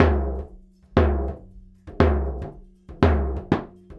120 TOMS02.wav